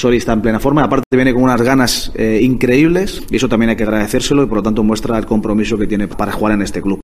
El entrenador de la UD Las Palmas, García Pimienta, compareció en la previa del partido ante la Real Sociedad, club al que destacó como "un ejemplo a seguir, poco a poco ha ido consiguiendo objetivos. Nunca va a renunciar a su manera de entender el juego".